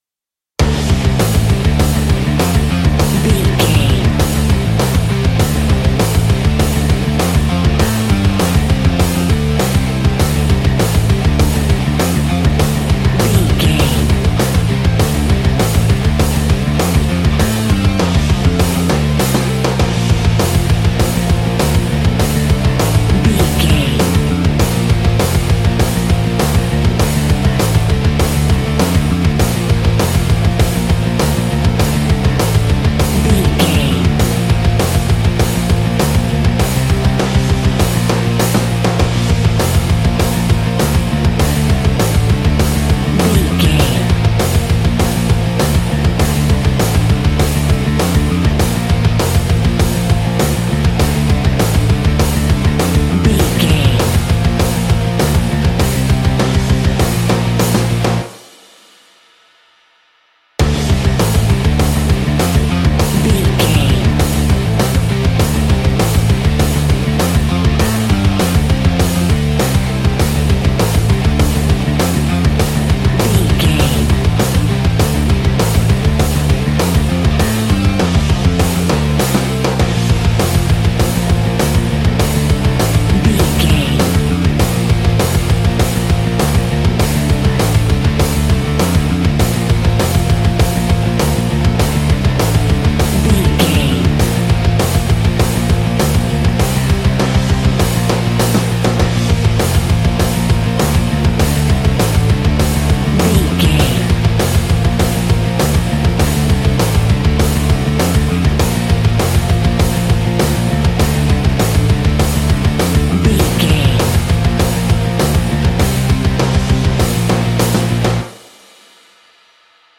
Aeolian/Minor
D
angry
powerful
aggressive
electric guitar
drums
bass guitar